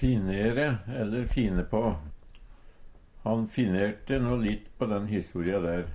DIALEKTORD PÅ NORMERT NORSK finnere/fine på å pynte på/gjere betre Infinitiv Presens Preteritum Perfektum å finnere Eksempel på bruk Han finnerte no litt på den historia der.